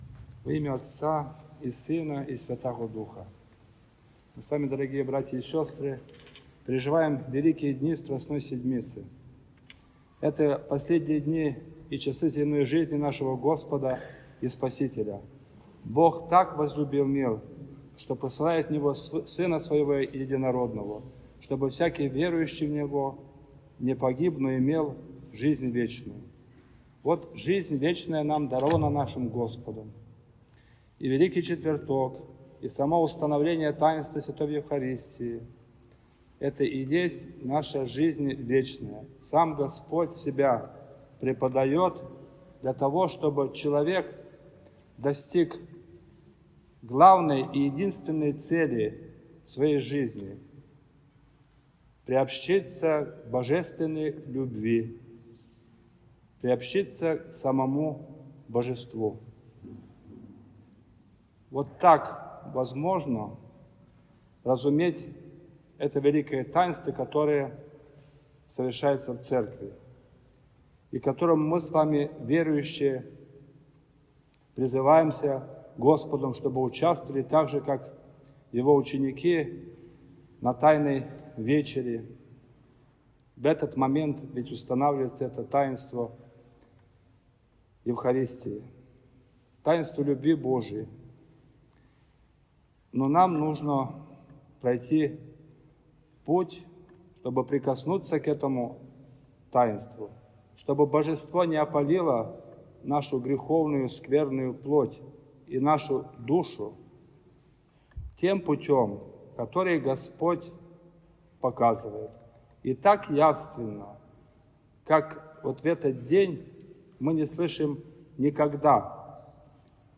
Великий-Четверток.-Проповедь.mp3